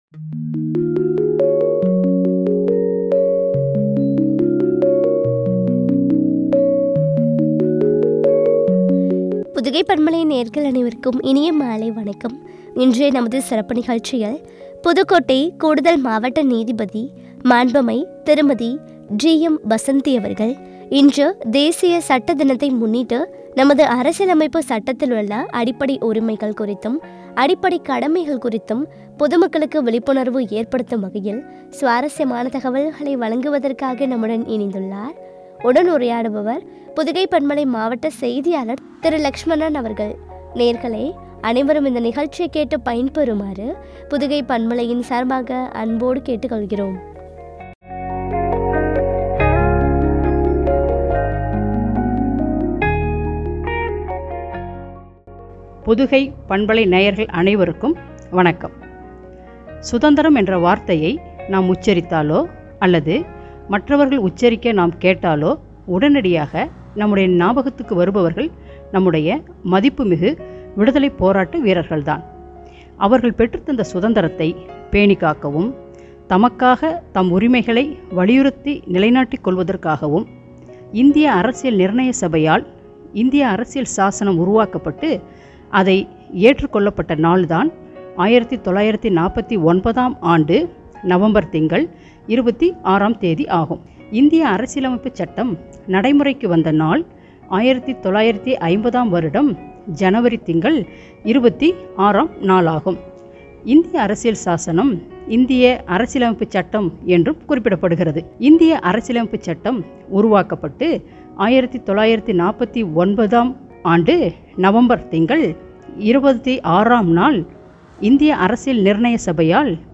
புதுக்கோட்டை, கூடுதல் மாவட்ட நீதிபதி, மாண்பமை திருமதி . G.M.வசந்தி அவர்கள் , “சட்டம் அறிவோம்” குறித்து வழங்கிய உரையாடல்.